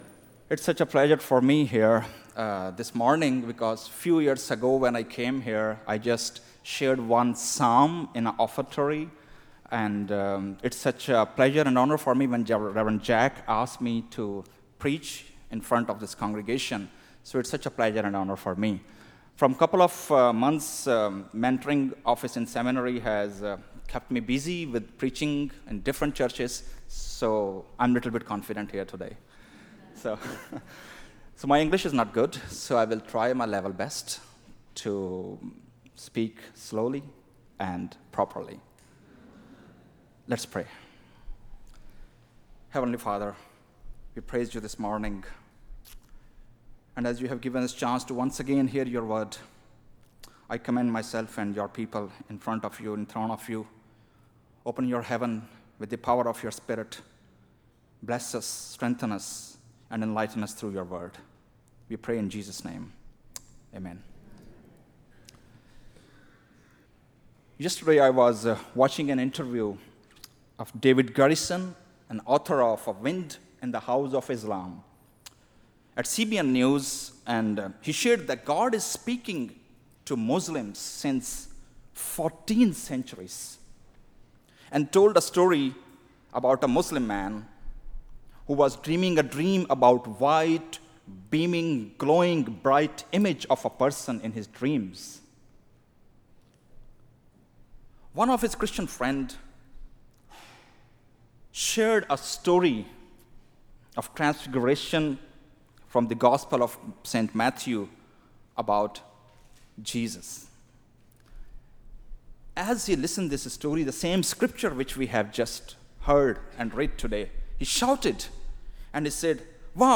2014 Sermons